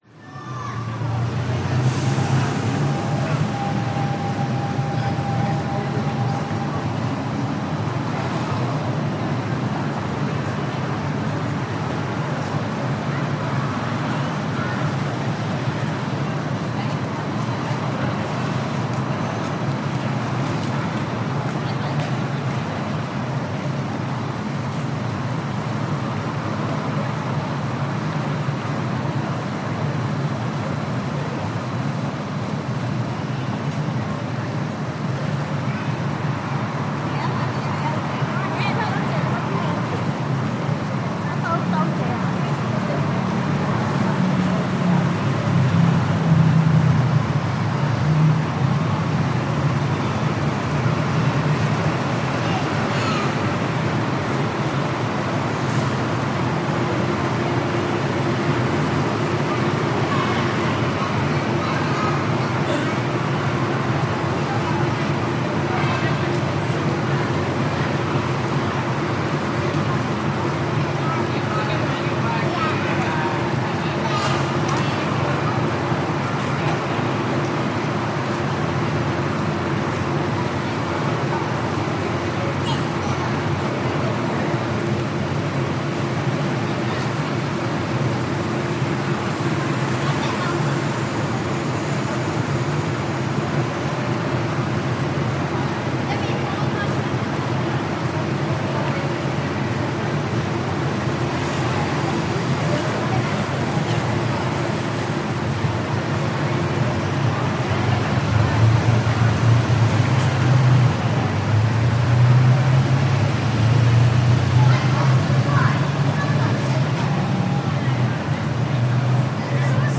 Шум у автобусной остановки